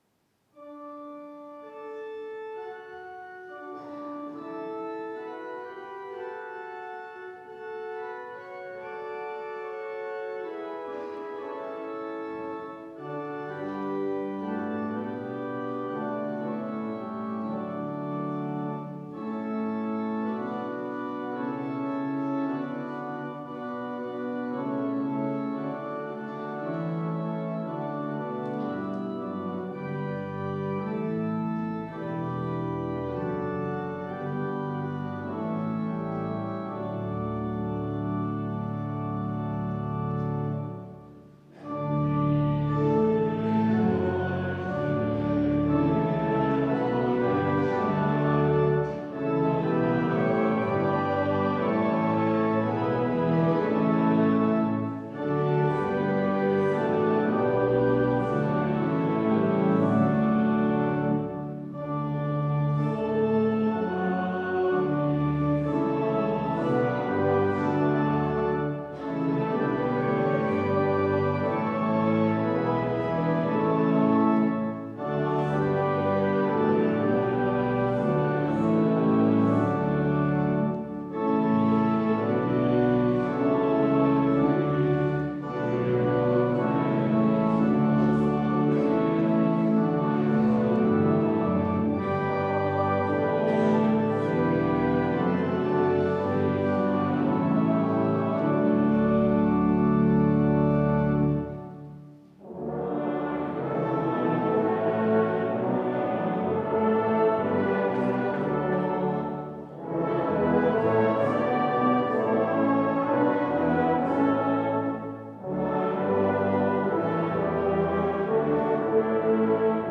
Wie schön leuchtet der Morgenstern... (LG 78,1-4) Ev.-Luth. St. Johannesgemeinde Zwickau-Planitz
Audiomitschnitt unseres Gottesdienstes zum Epipaniasfest 2026.